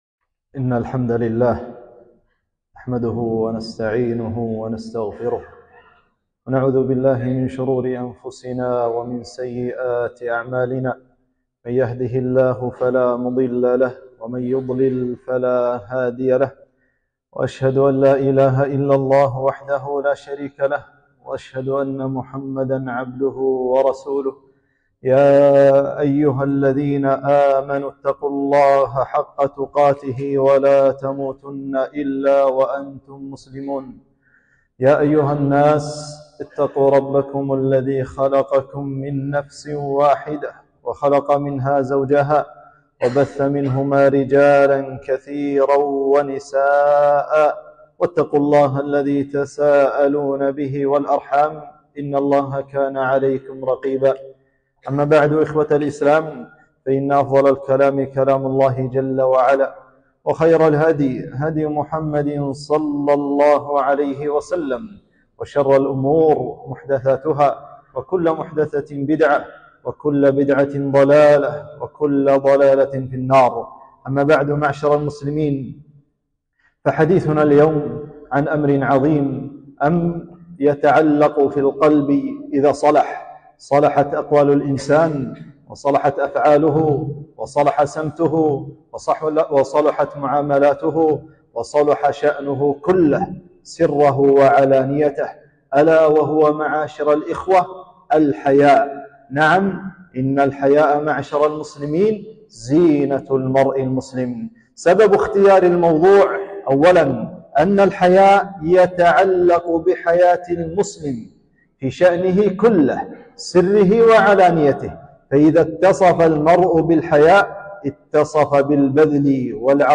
خطبة - الحياء من الإيمان